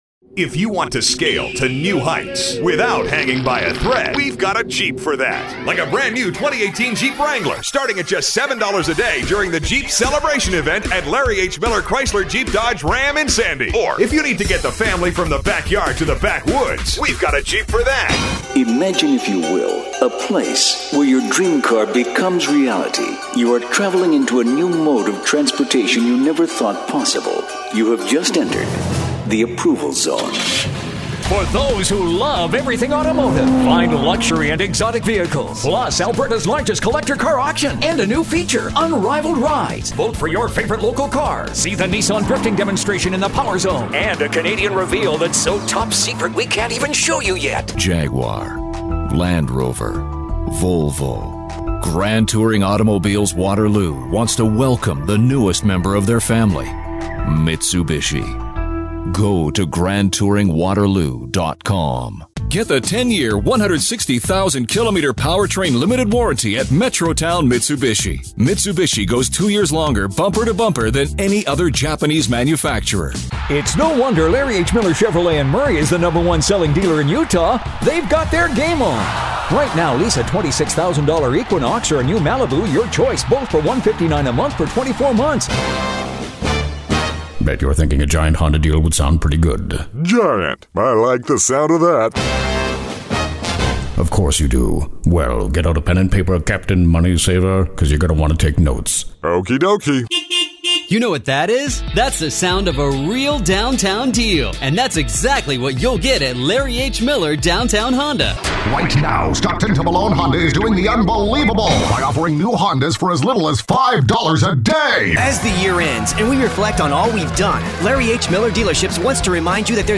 Automotive Commercials – Males Voices
AutomotiveMaleDemo.mp3